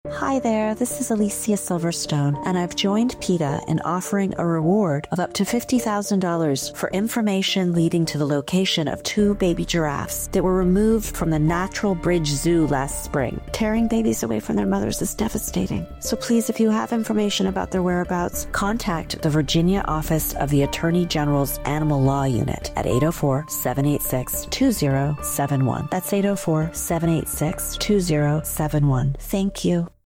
Instructions for Downloading This Radio PSA Audio File